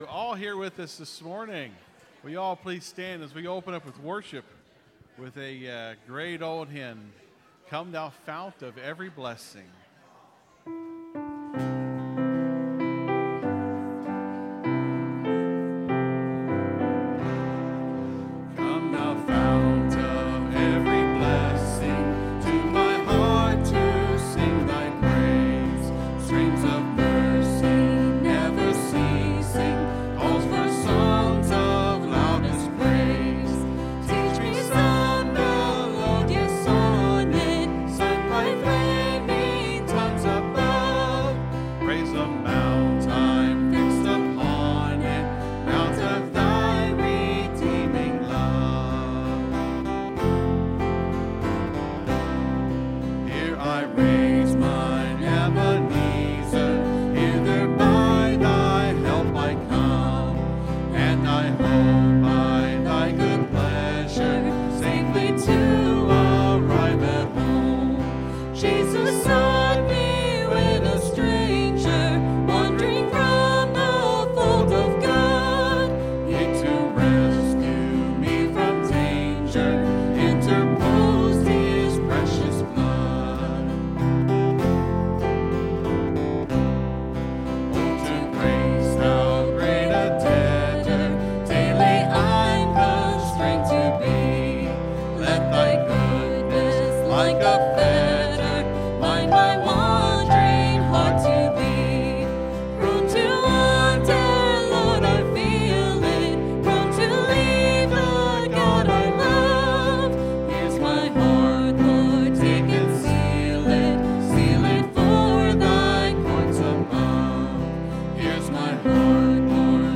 (Sermon starts at 20:30 in the recording).